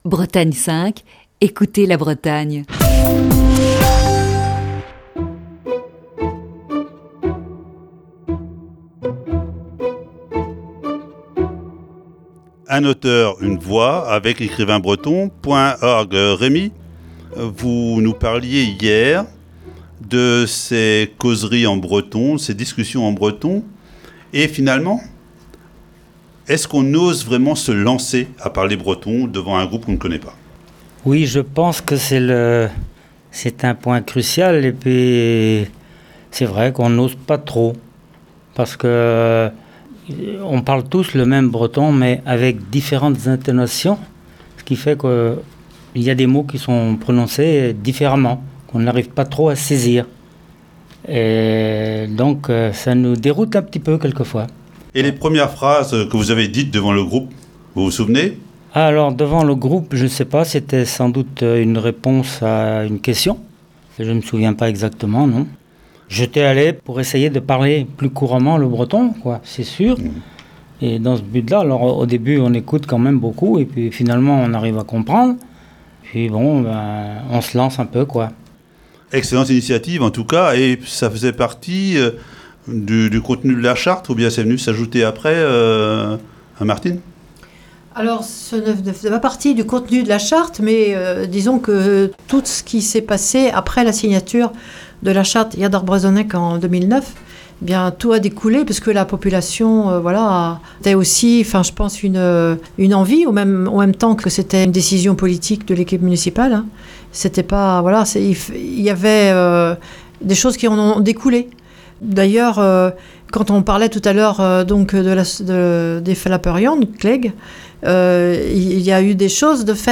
Émission du 22 juin 2021.